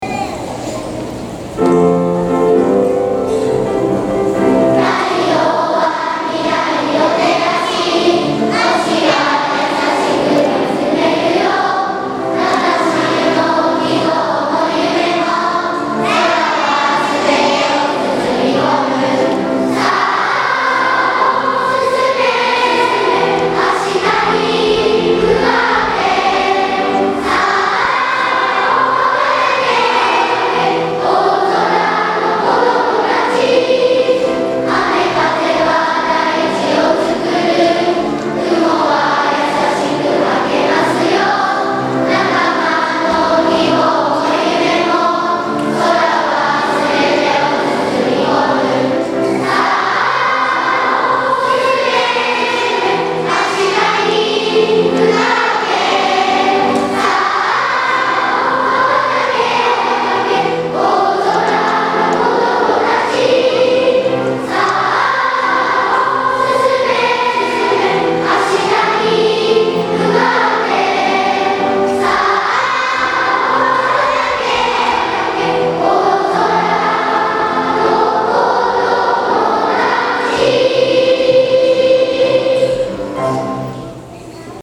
大空創立記念コンサート
大空の子どもたち」全校２部合唱です。